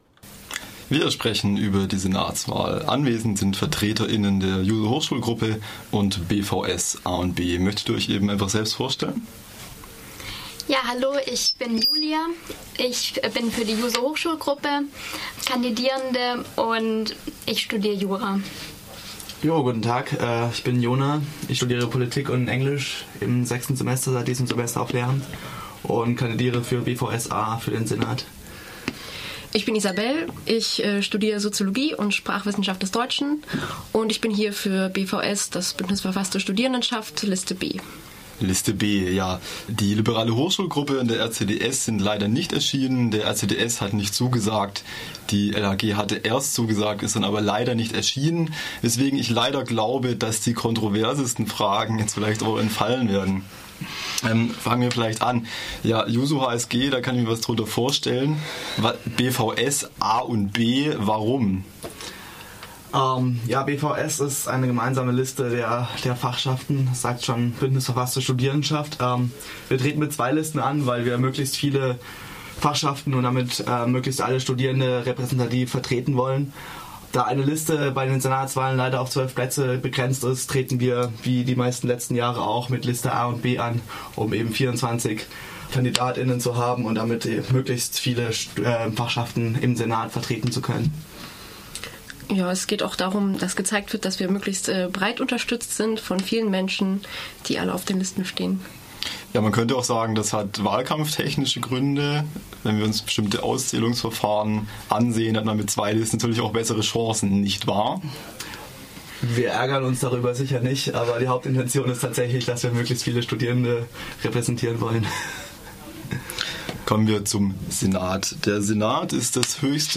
Studiodebatte über Freiburger Uniwahl - Fokus Europa